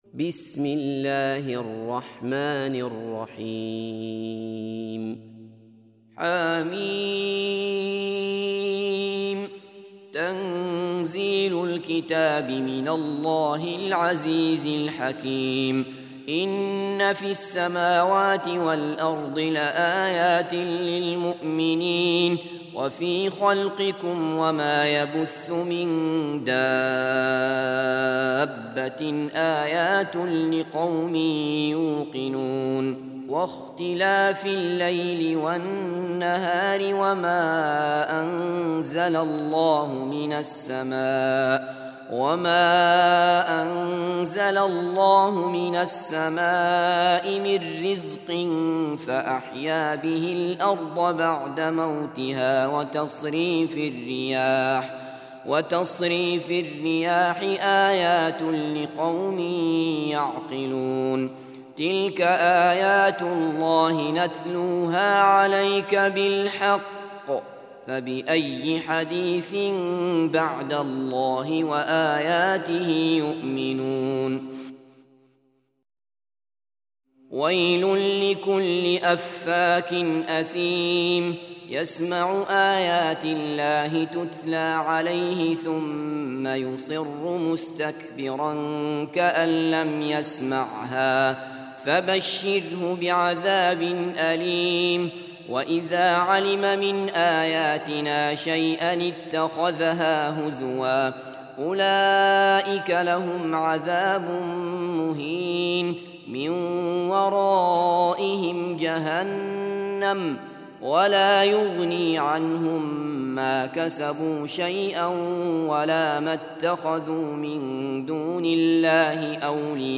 45. Surah Al-J�thiya سورة الجاثية Audio Quran Tarteel Recitation
Surah Sequence تتابع السورة Download Surah حمّل السورة Reciting Murattalah Audio for 45.